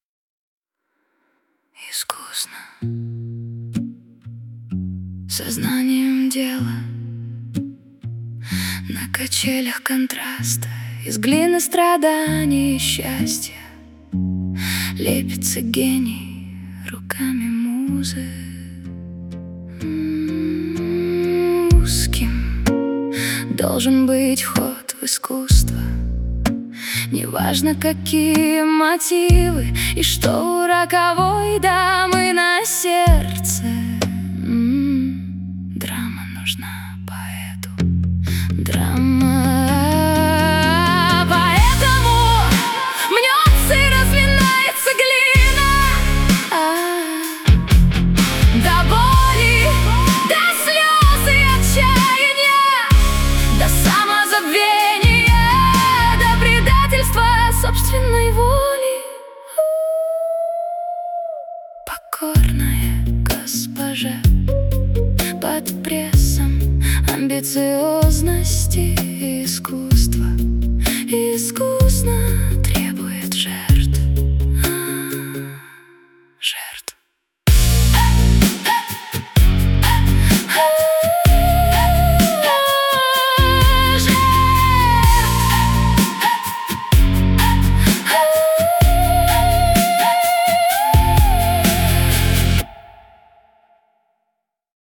mp3,2035k] Авторская песня